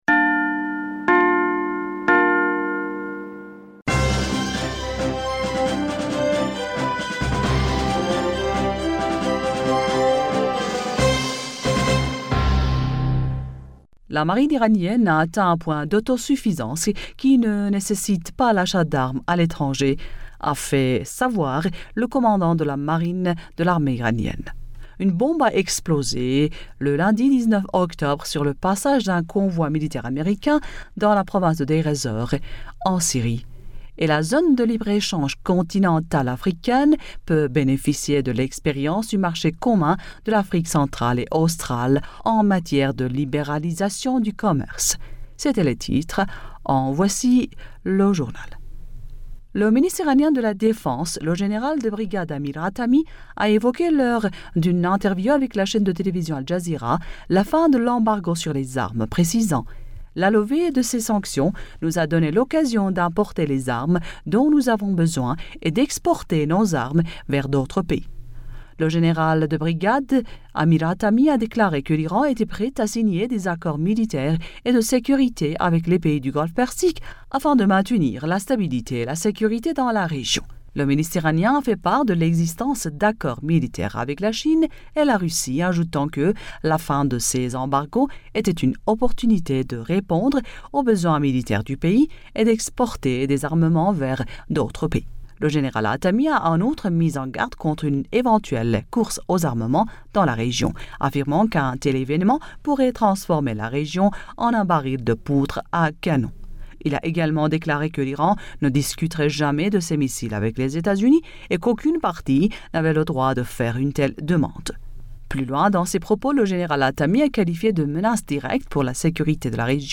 Bulletin d'information du 20 Octobre 2020